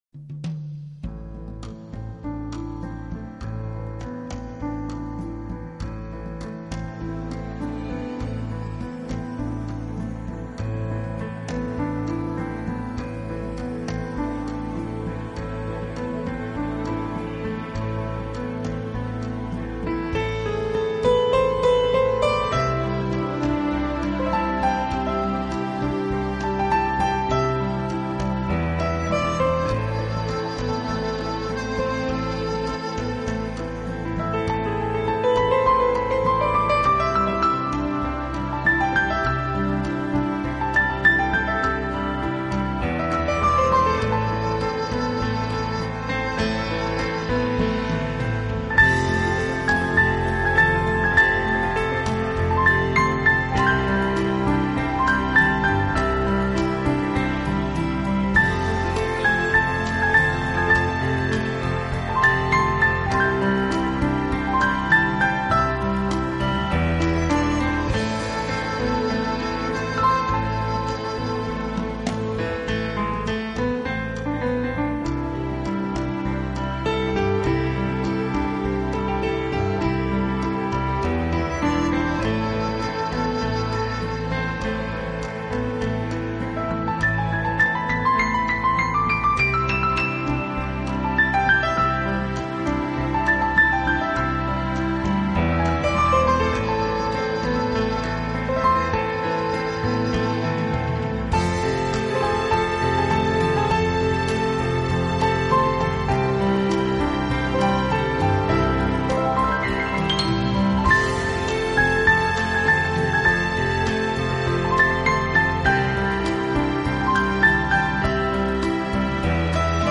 【新世纪钢琴】
音乐风格: Newage